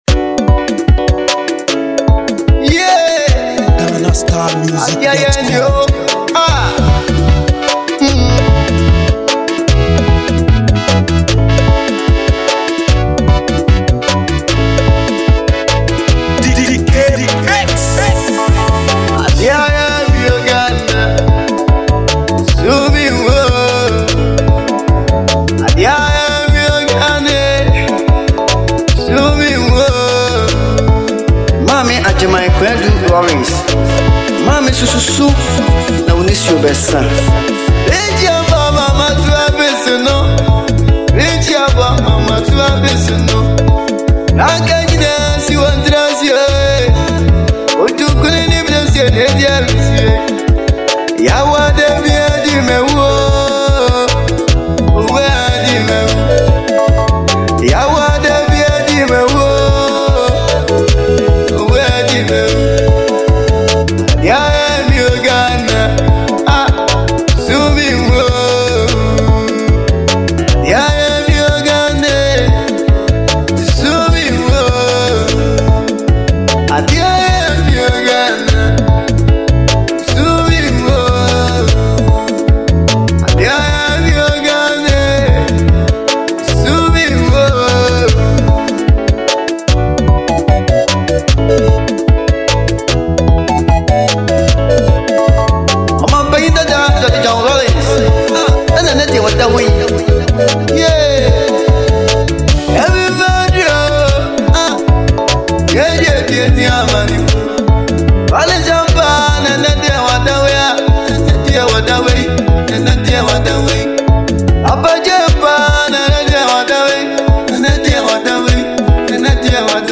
Ghana Music
Ghanaian Hiplife artist